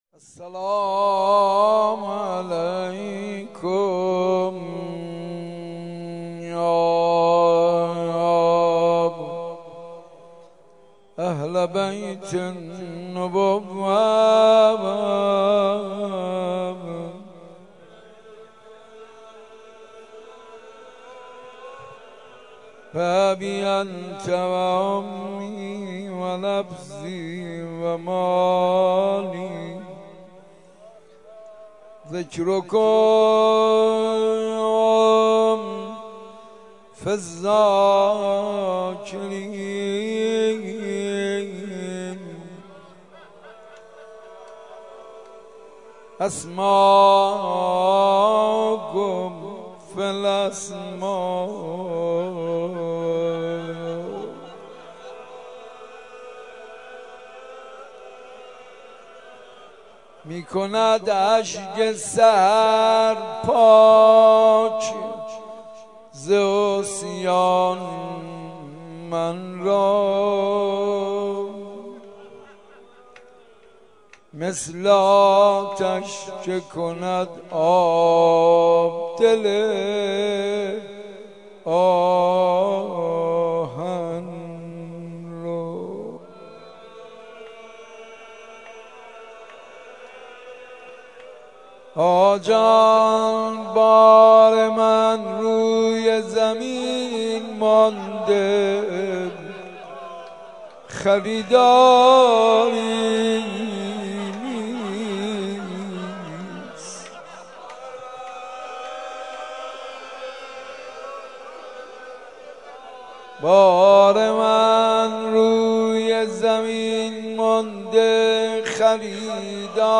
شب اربعین در حسینیه مرحوم چمنی
با مداحی حاج منصور ارضی برگزار گردید